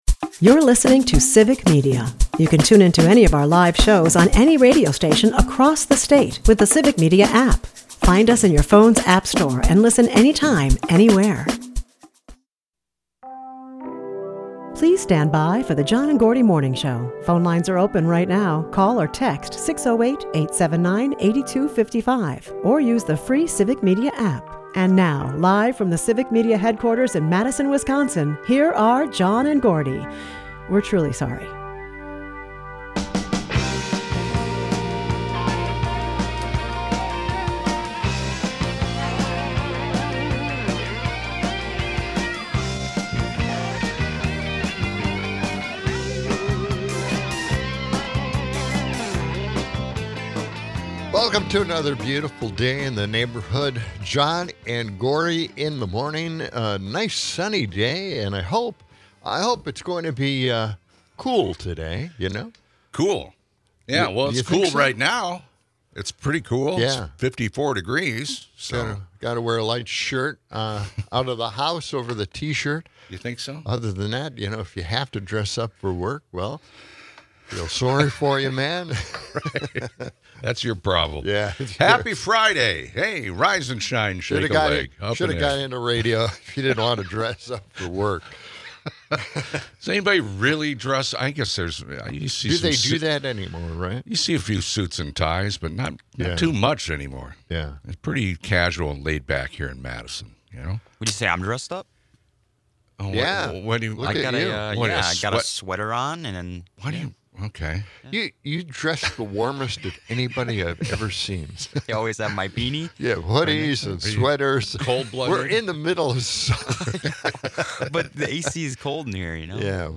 They also touch on Trump's tariffs and their impact on healthcare costs, set against the backdrop of Trump’s latest antics. The show blends light-hearted jokes with biting political commentary.